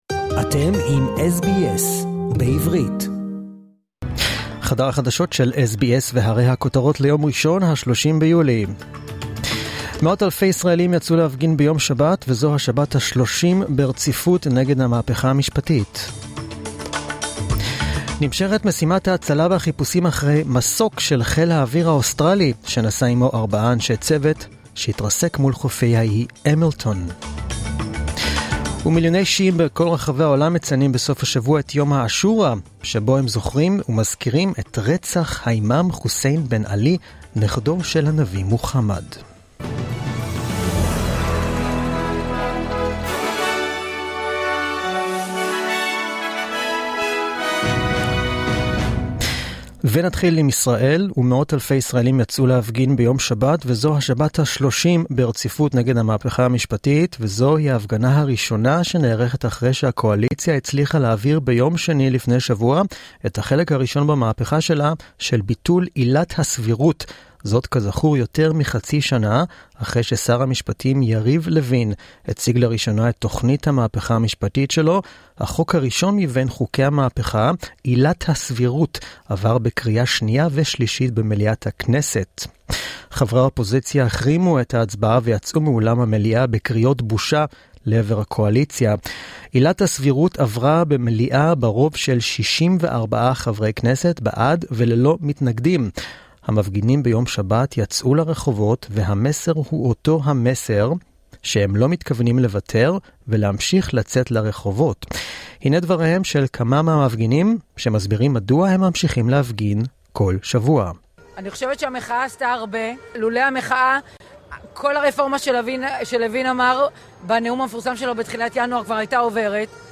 The latest news in Hebrew, as heard on the SBS Hebrew program